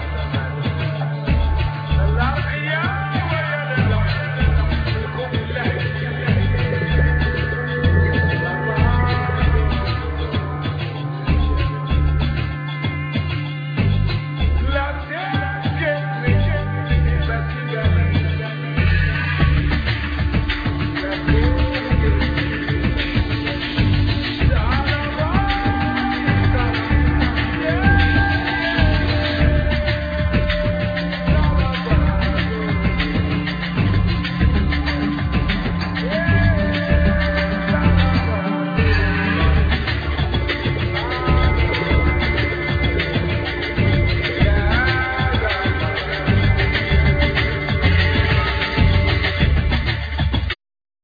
Voice,Poetry,Samples
Violin
Singing